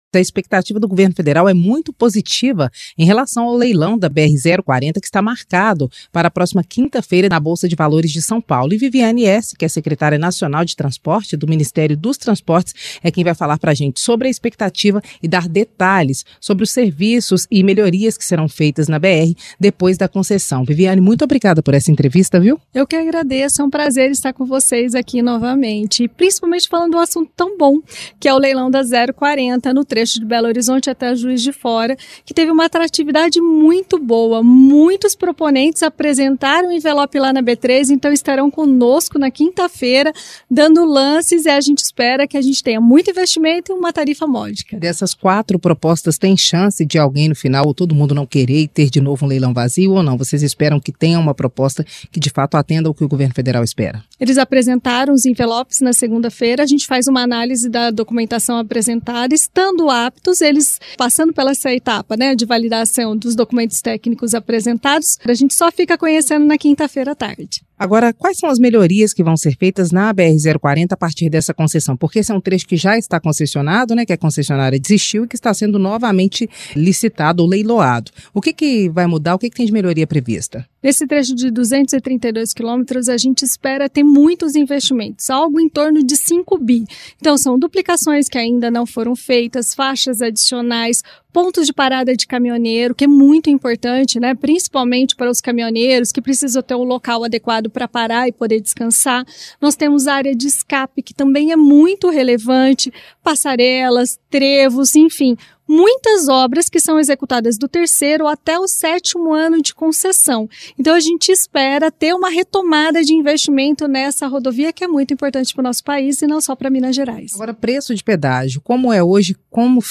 Vivane Esse, Secretária Nacional de Transportes conversou com a repórter